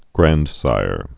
(grăndsīr, grăn-)